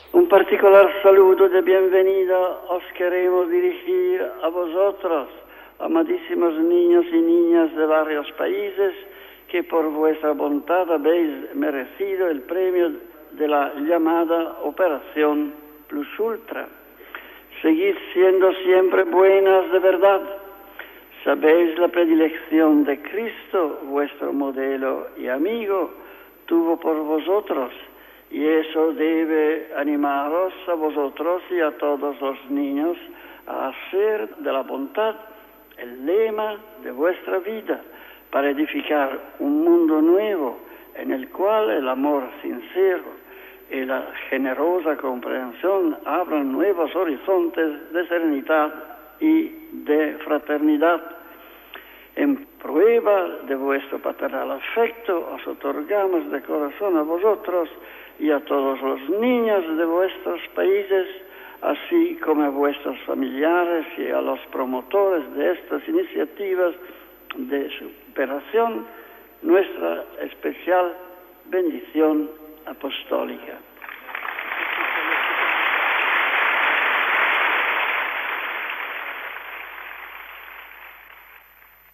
Paraules del Sant Pare Pau VI als nens de l'Operación Plus Ultra durant la recepció feta a Ciutat del Vaticà